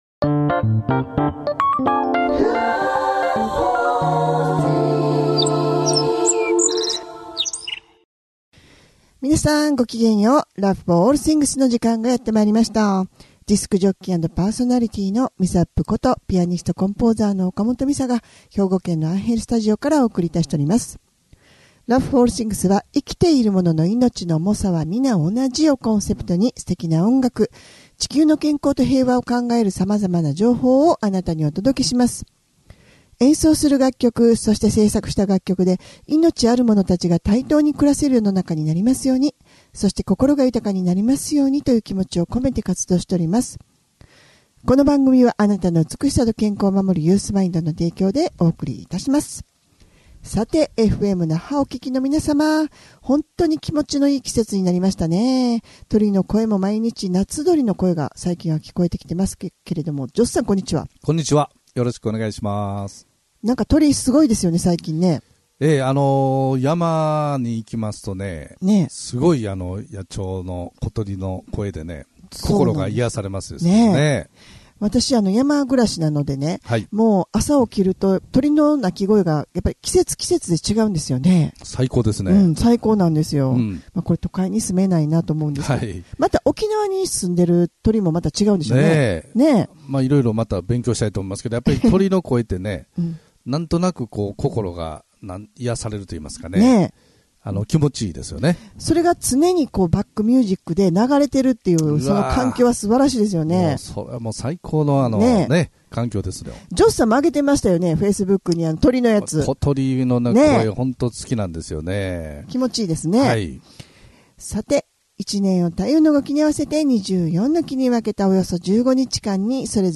生きているものの命の重さは同じというコンセプトで音楽とおしゃべりでお送りする番組です♪